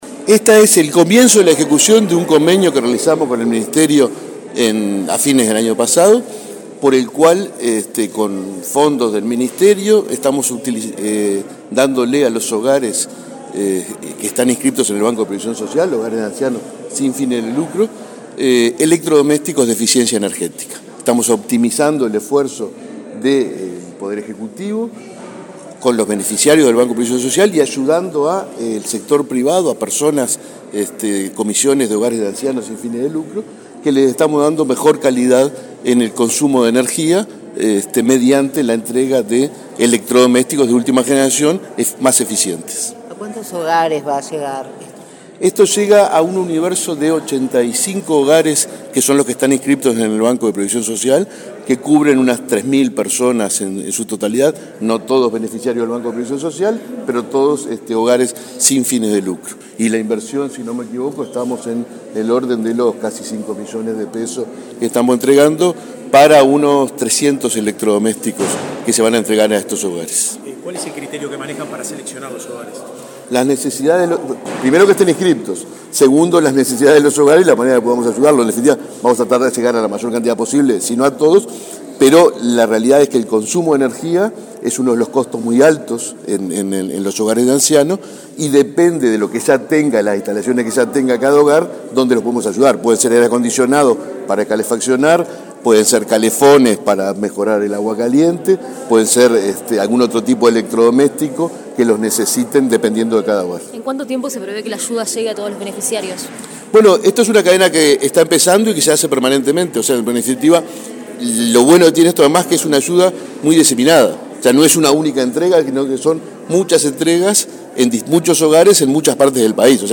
Declaraciones del presidente del BPS y el director de Energía del MIEM
Declaraciones del presidente del BPS y el director de Energía del MIEM 16/06/2023 Compartir Facebook X Copiar enlace WhatsApp LinkedIn Este viernes 16, el Banco de Previsión Social (BPS) y el Ministerio de Industria, Energía y Minería (MIEM) entregaron equipos eléctricos eficientes a un hogar de ancianos. El director nacional de Energía, Fitgerald Cantero, y el presidente del organismo previsional, Alfredo Cabrera, dialogaron luego con la prensa.